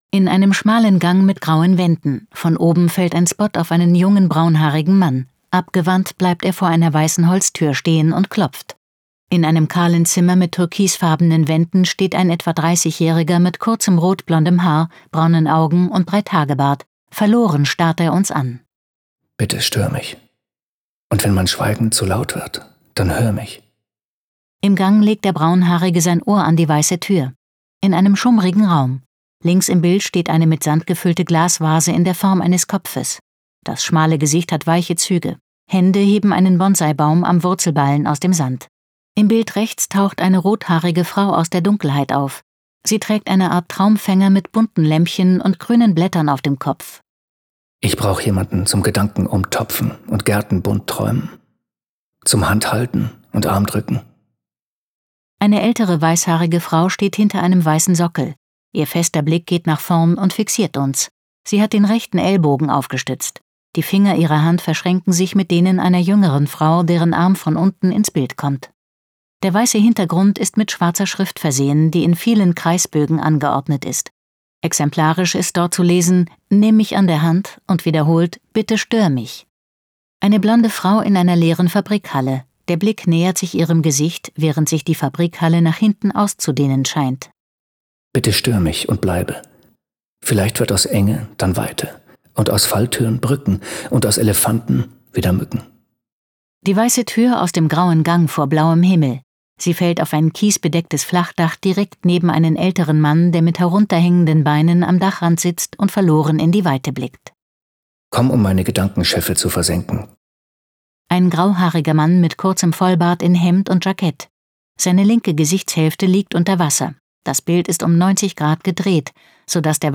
audiodeskription_spot_bitte-stoer-mich_schnitt.wav